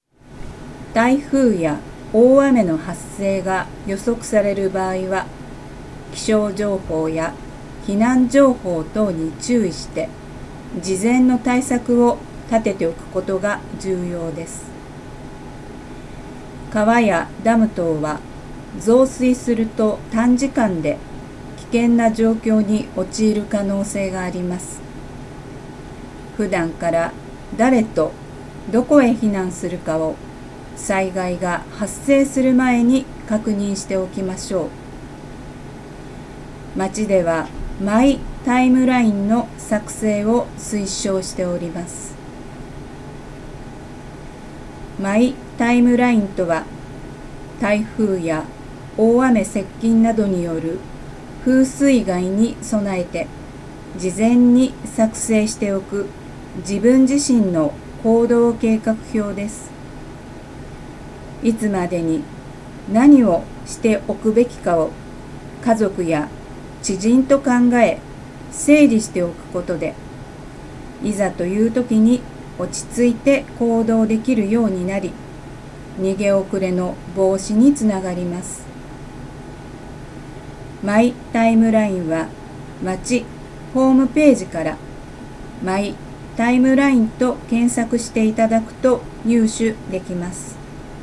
女性消防サポーターによる「音声版ハザードマップ」
女性消防サポーターが、目が不自由な方に向けての防災に関する知識や災害リスク情報を読み上げ、音声で発信します。